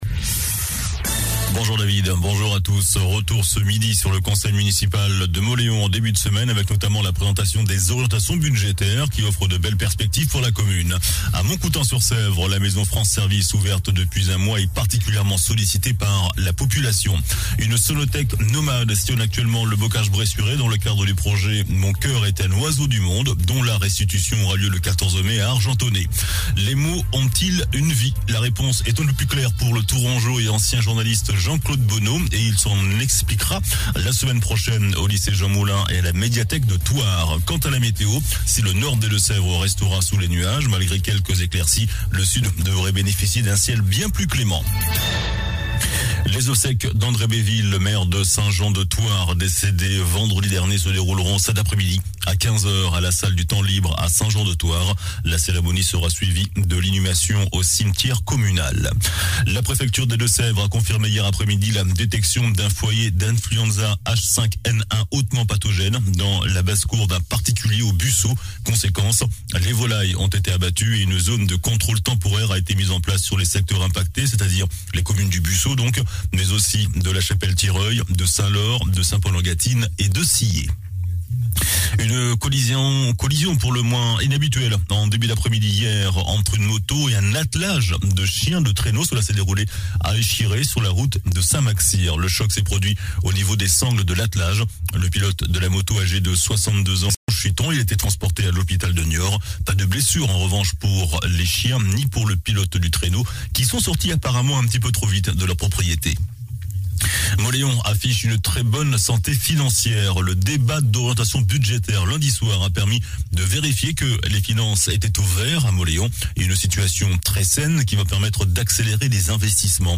JOURNAL DU MERCREDI 23 FEVRIER ( MIDI )